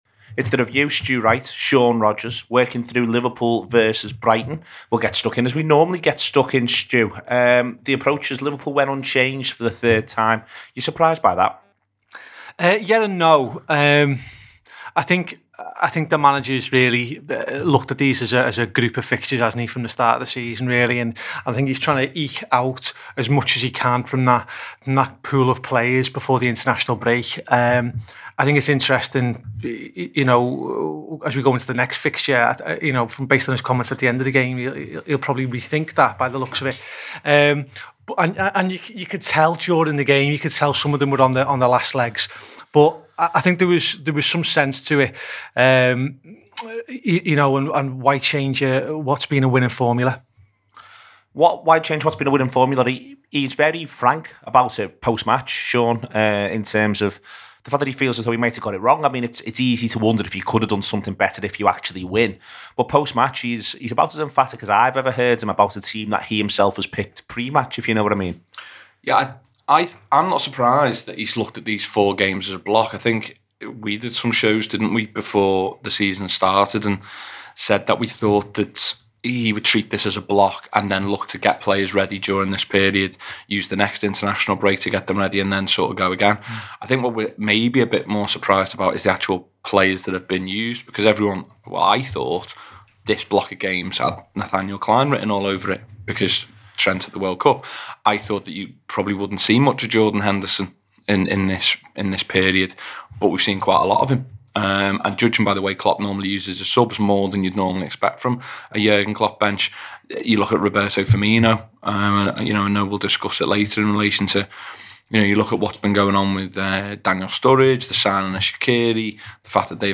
hosts